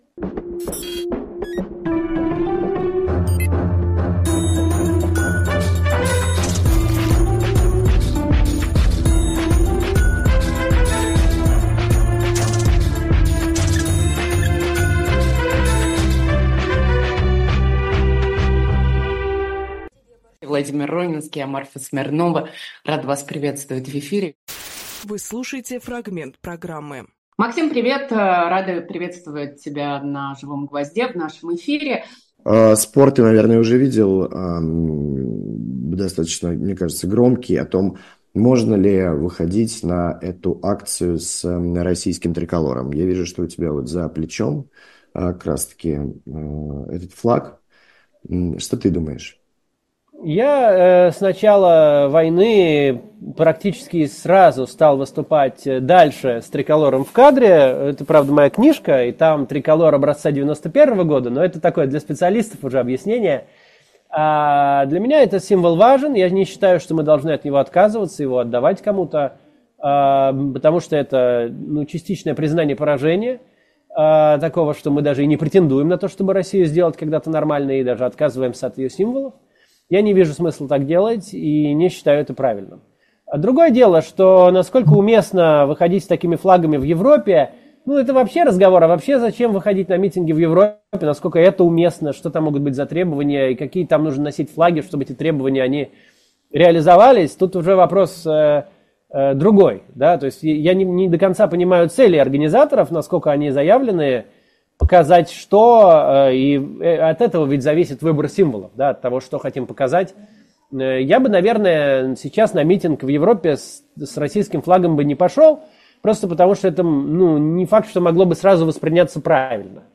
Фрагмент эфира от 05.11